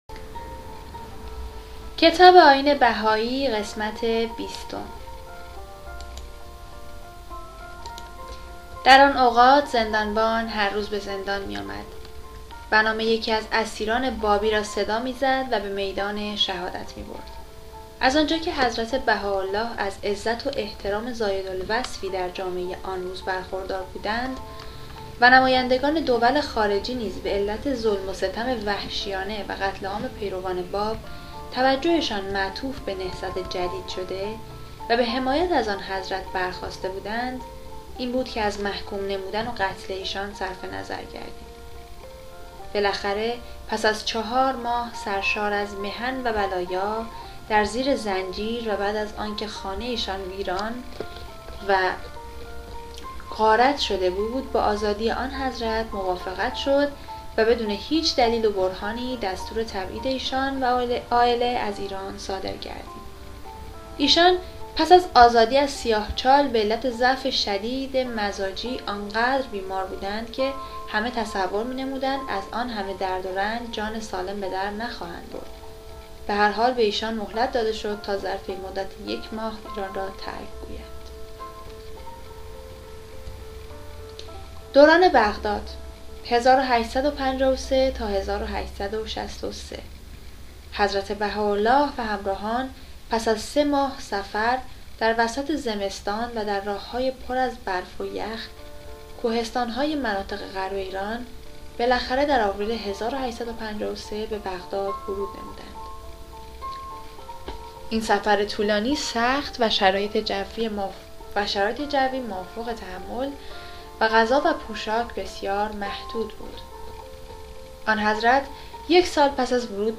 کتاب صوتی «نگرشی کوتاه به تاریخ و تعالیم دیانت بهائی» | تعالیم و عقاید آئین بهائی